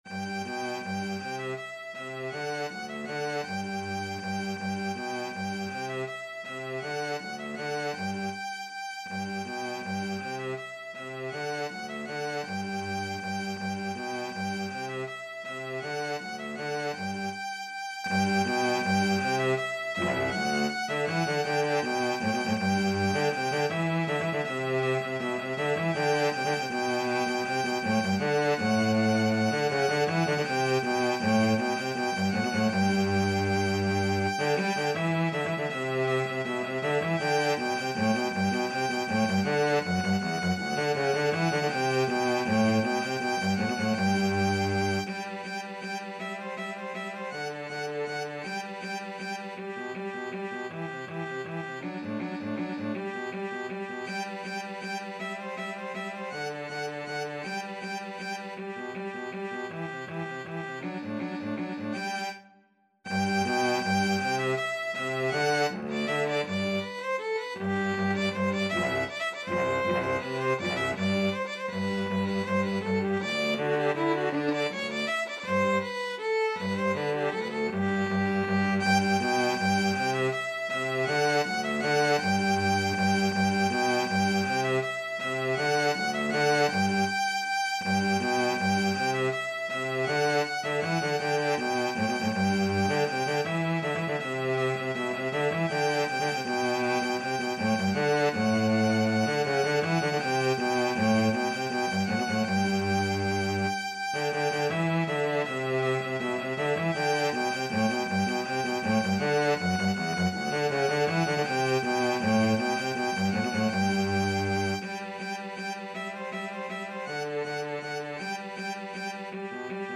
3/4 (View more 3/4 Music)
Allegro Vivo = 160 (View more music marked Allegro)
Classical (View more Classical Violin-Cello Duet Music)